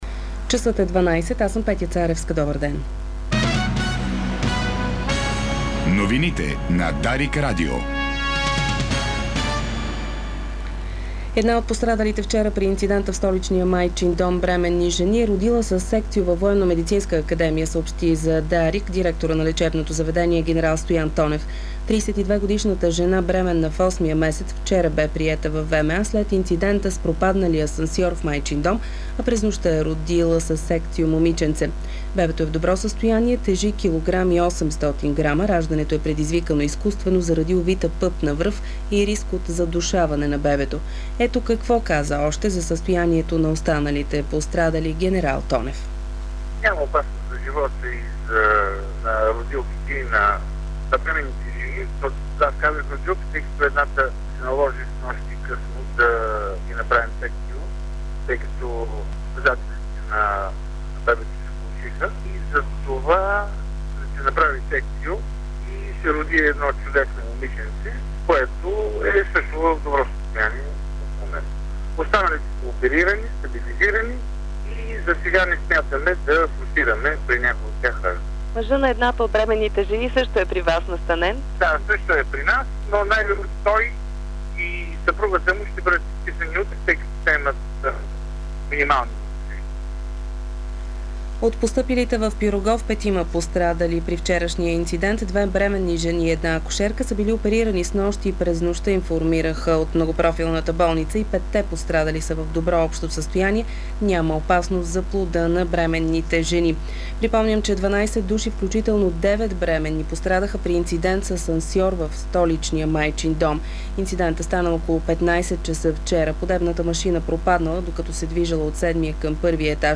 Обзорна информационна емисия - 03.03.2010